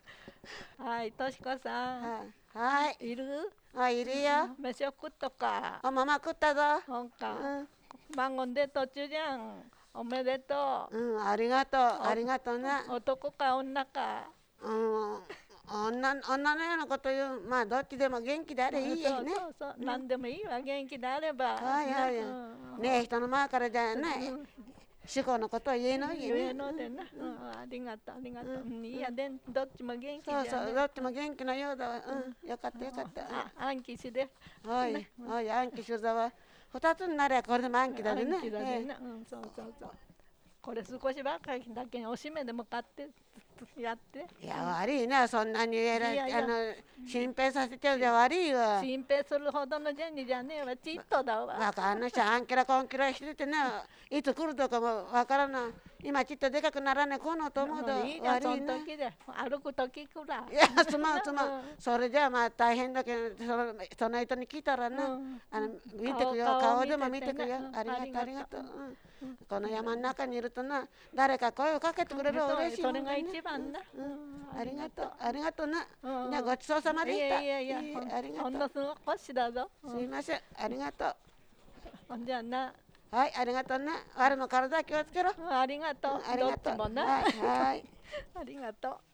会話（ロールプレイ） ─茂倉─
[4] あんきしゅざわ：あんきしゅざわ [aŋkiɕɯdzawa] と聞こえるが、話者によると「あんきしるざわ」と言っているとのこと。
[13] すこ゜しだぞ：「こ゜」の後にわずかな間がある。
[14] 「わ」が「あ」に近く聞こえる。